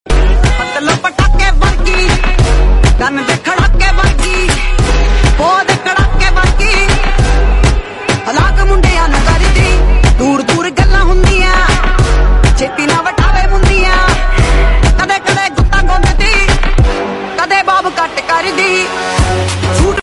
Hearing some whistle (turbo sonud sound effects free download